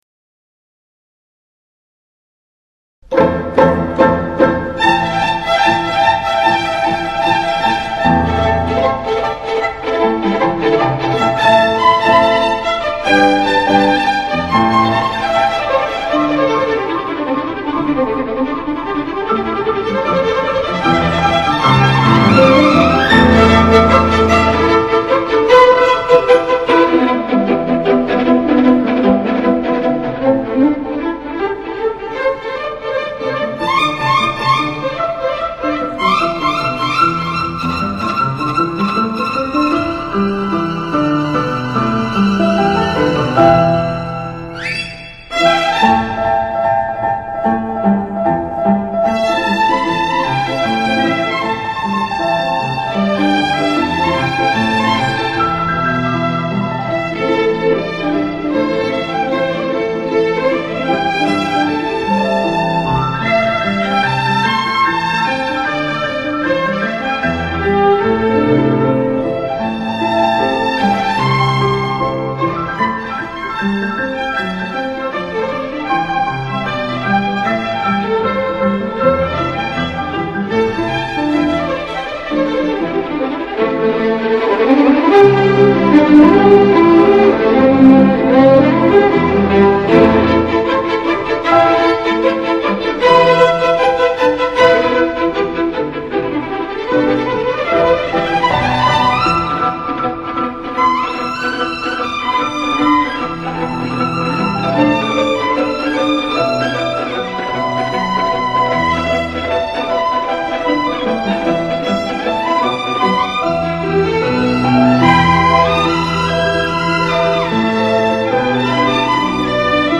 小提琴齐奏曲